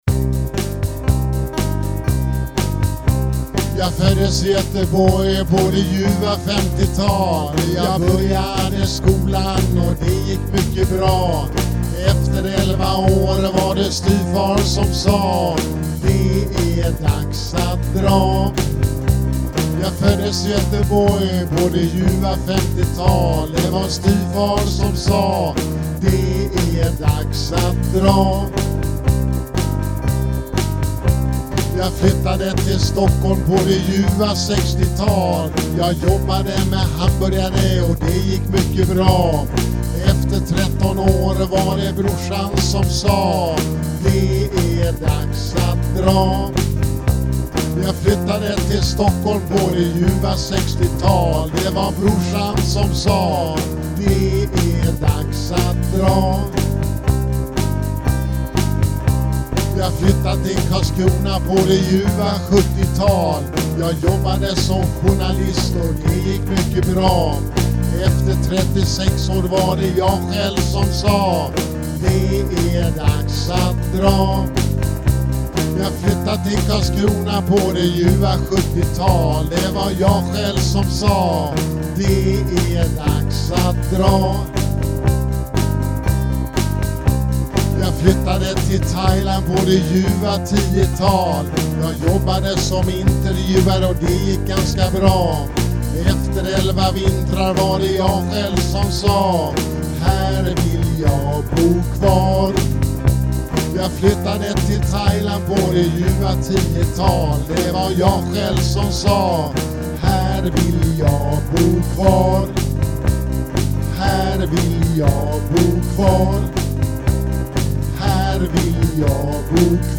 Jag har länge velat skriva en låt med mollackorden Am, Dm och Em med snygga basupp/nedgångar via C och G. Dammade av rundan 2013-09-13 kl 17:30 på loftgången i Pattaya och det lät bra.